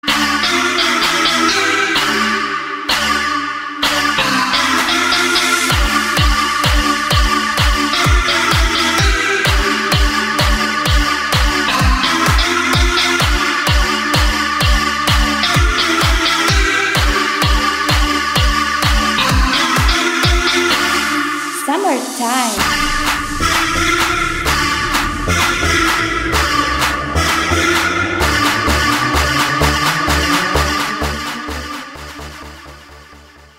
• Качество: 192, Stereo
ритмичные
атмосферные
dance
EDM
без слов
энергичные
house
динамичные